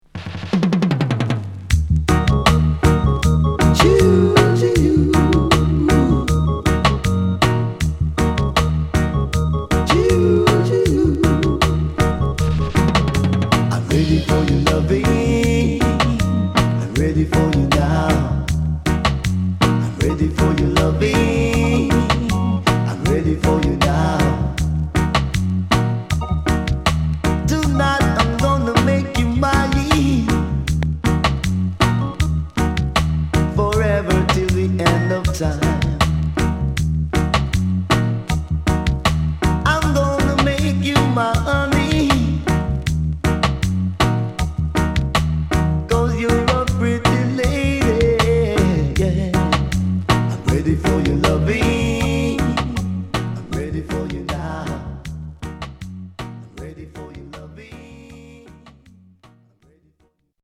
HOME > Back Order [DANCEHALL LP]
SIDE B:盤質は少しチリノイズ入りますが良好です。